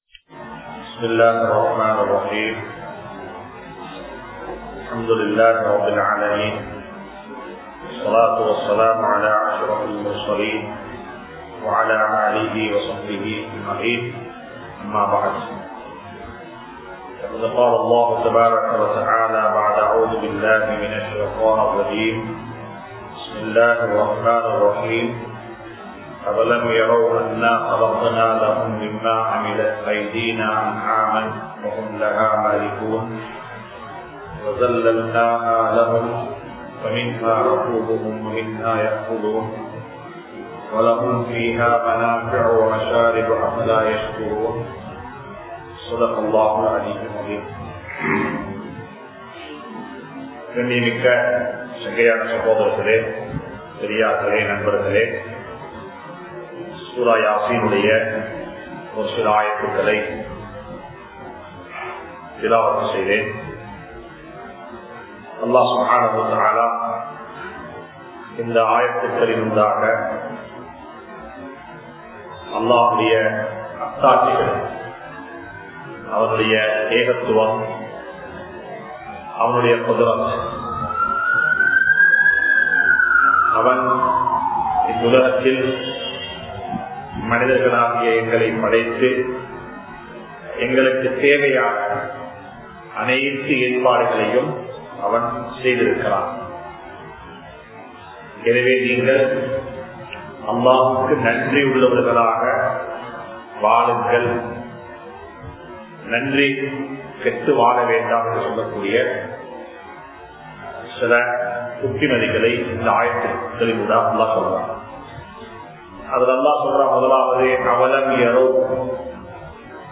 Surah Yaseen(Thafseer) | Audio Bayans | All Ceylon Muslim Youth Community | Addalaichenai